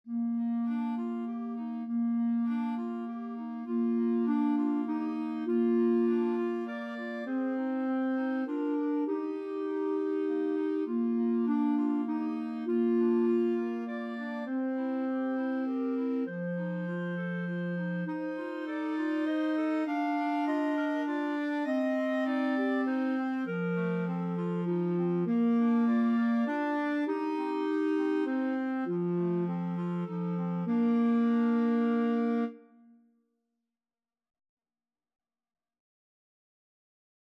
17th-century English folk song.
Bb major (Sounding Pitch) C major (Clarinet in Bb) (View more Bb major Music for Clarinet Trio )
Moderato
3/4 (View more 3/4 Music)
Clarinet Trio  (View more Easy Clarinet Trio Music)